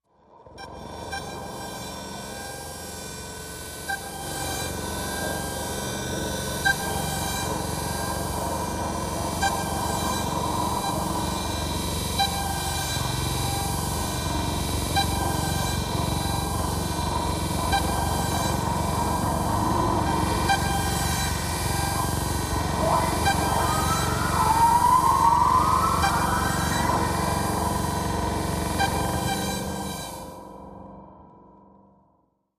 Message of Fear, Machine, Laser, Grinder, Arc, Electric